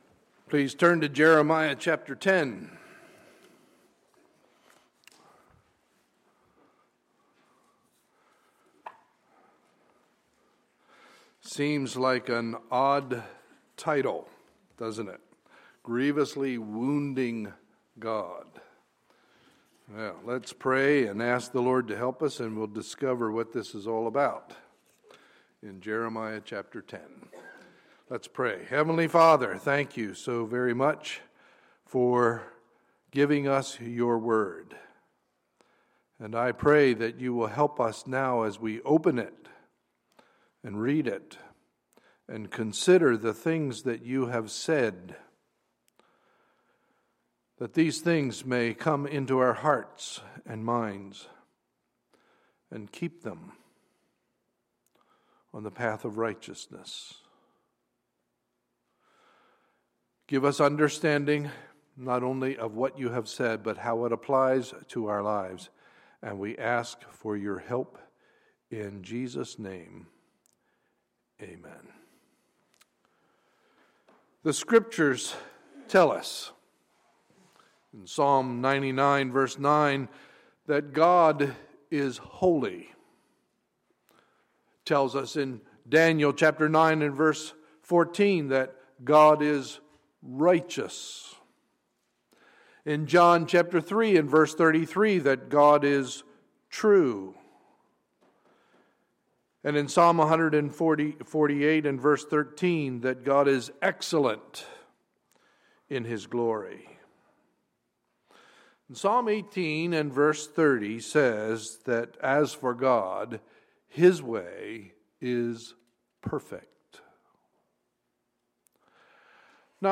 Sunday, March 22, 2015 – Sunday Morning Service